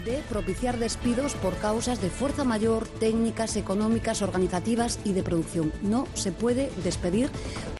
La ministra Díaz anunciando la prohibición de despidos por coronavirus